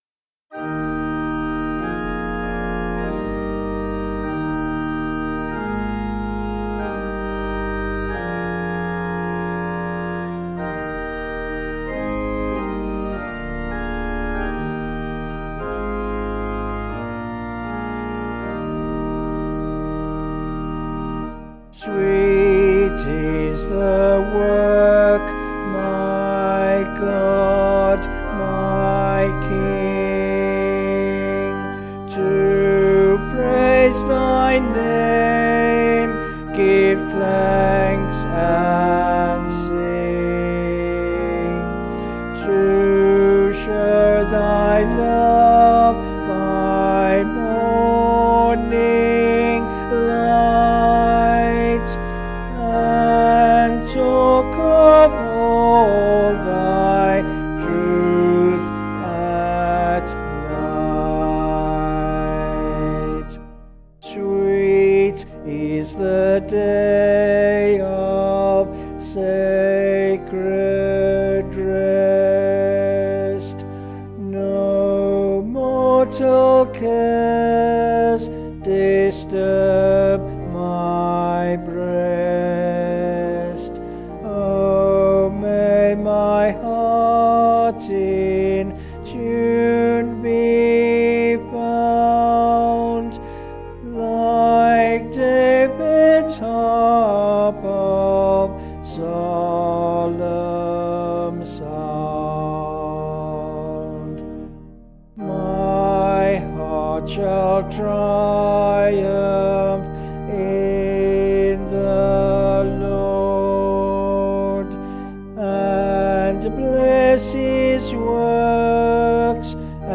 Vocals and Organ   264.8kb Sung Lyrics